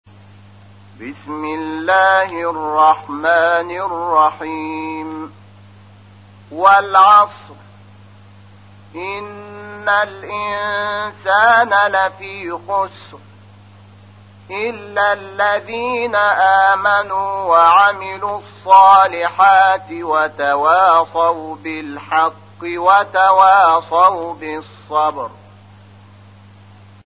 تحميل : 103. سورة العصر / القارئ شحات محمد انور / القرآن الكريم / موقع يا حسين